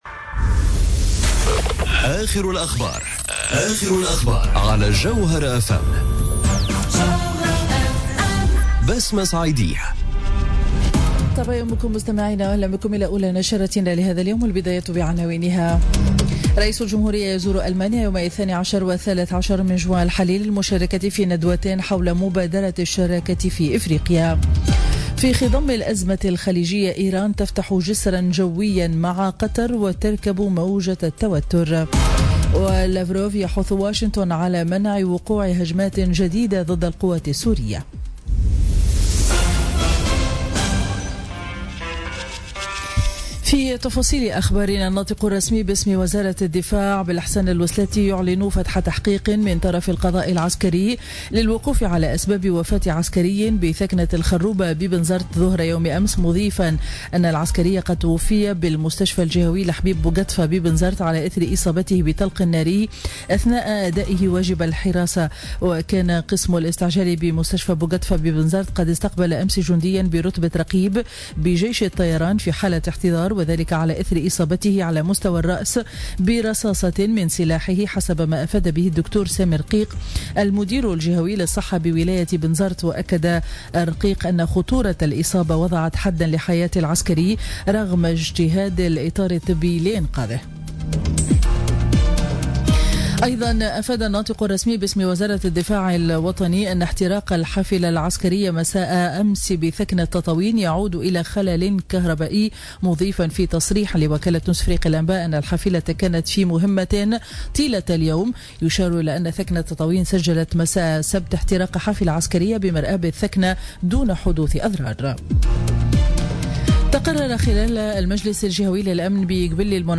نشرة أخبار السابعة صباحا ليوم الأحد 11 جوان 2017